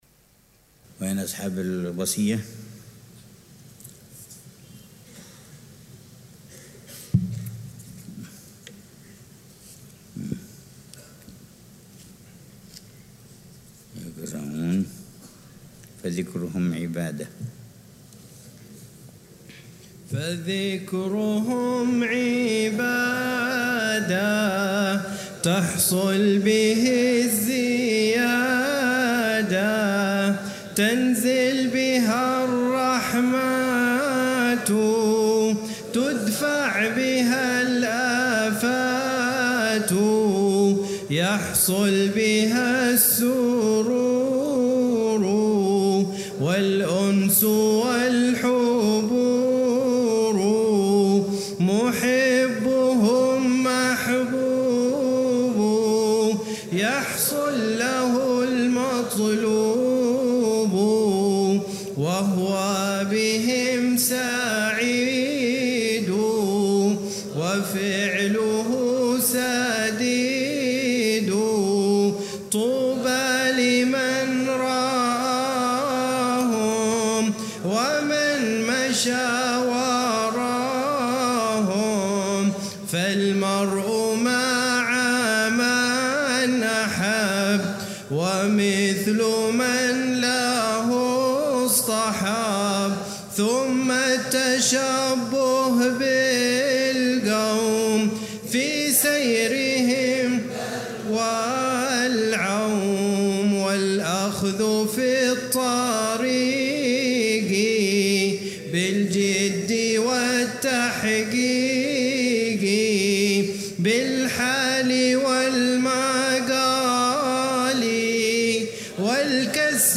شرح الحبيب عمر بن حفيظ على منظومة «هدية الصديق للأخ والرفيق» للحبيب عبد الله بن حسين بن طاهر. الدرس الثاني عشر (24 محرم 1447هـ)